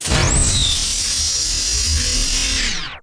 DOOROP3.WAV